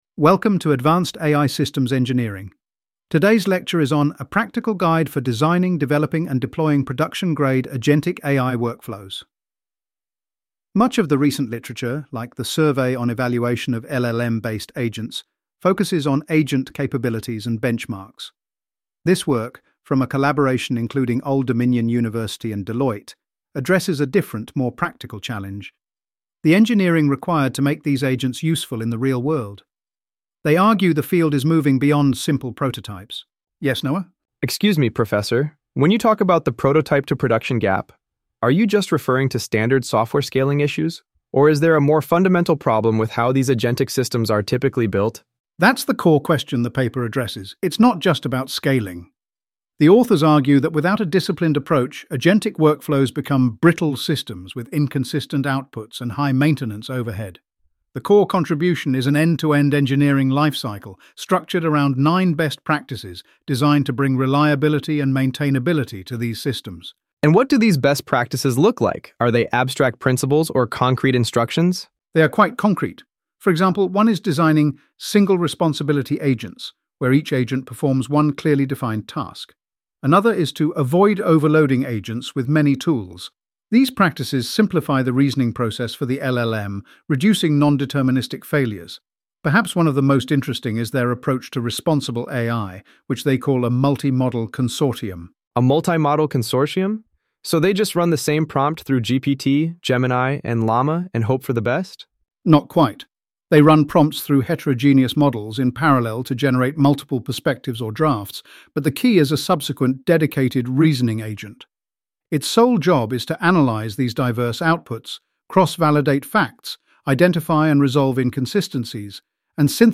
AI Audio Lecture + Q&A